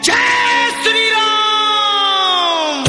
bhajan song